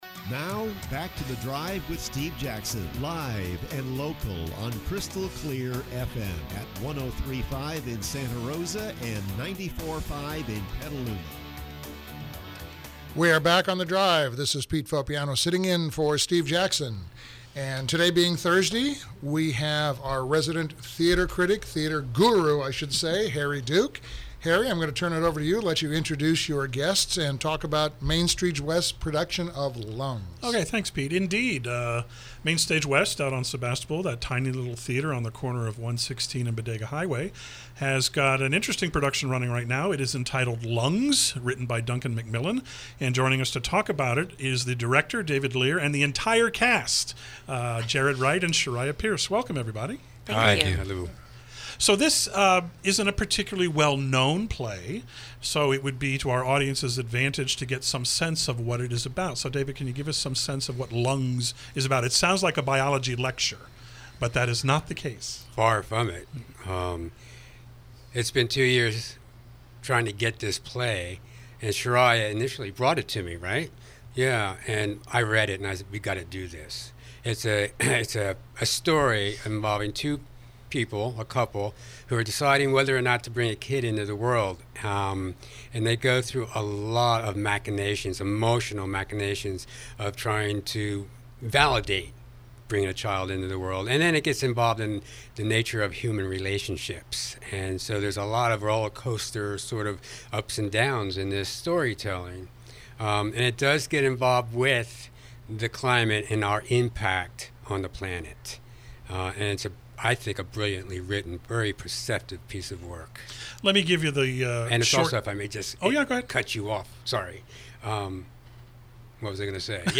KSRO Interview: “Lungs”